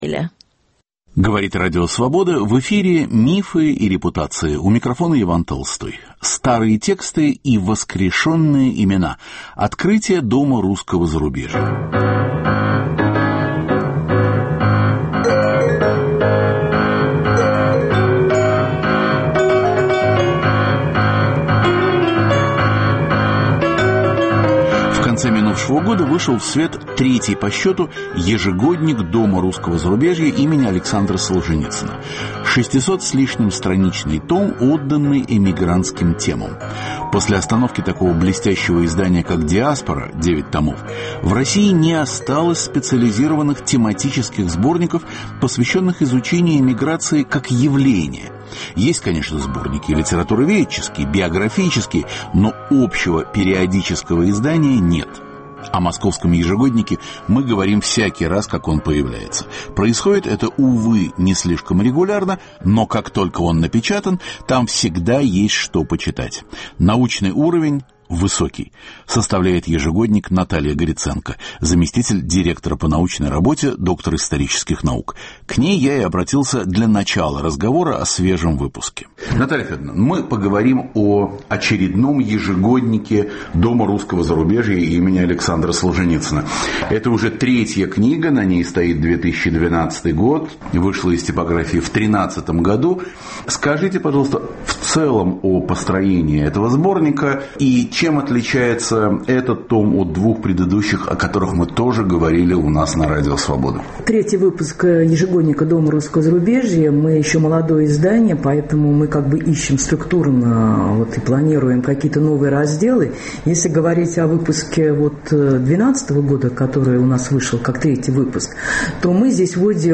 Старые тесты и воскрешенные имена: Открытия Дома русского зарубежья. Разговор